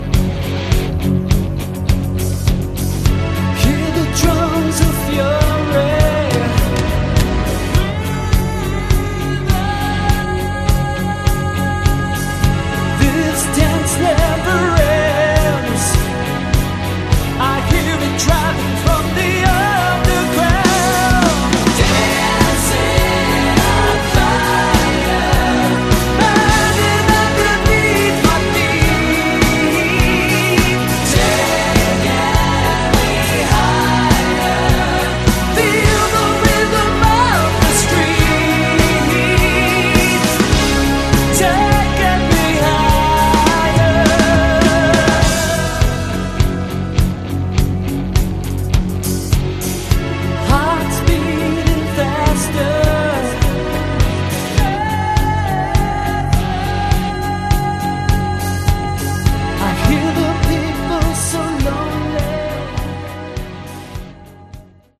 Category: AOR
vocals
guitars, backing vocals